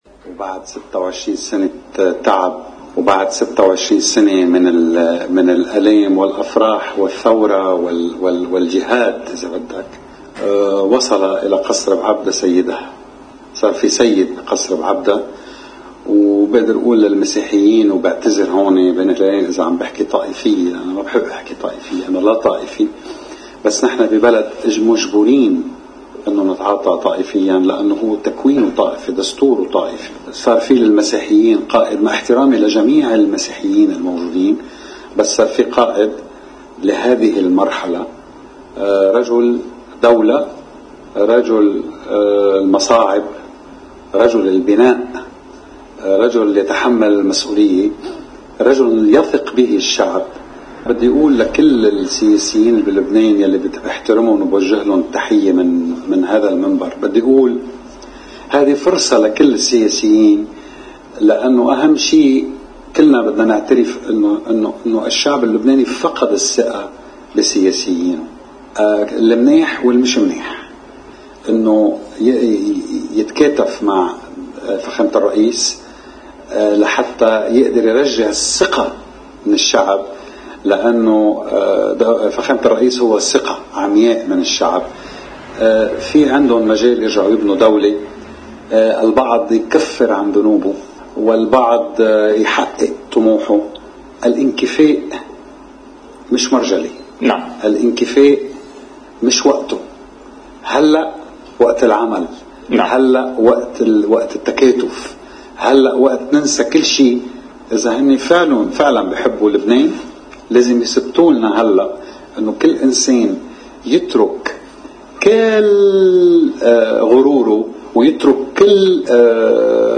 إعتبر الفنان سمير صفير في حديث لقناة الـ”OTV”، أنّه بعد مضي 26 عاماً من التعب والنضال والآلام والأفراح والثورة والجهاد، وصل الى قصر بعبدا سيدّه.